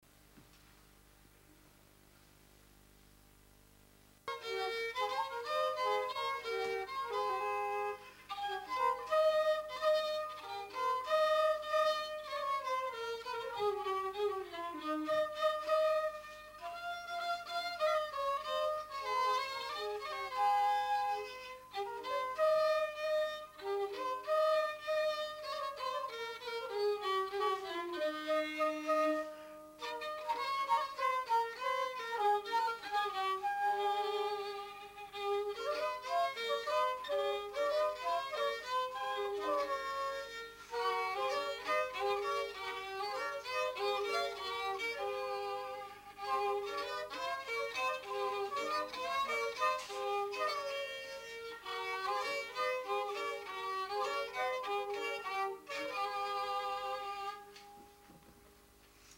Tipo de rexistro: Musical
Feito rexistrado: Evento
Soporte orixinal: Videocasete (VHS)
Xénero: Valse
Instrumentos: Violín, Frauta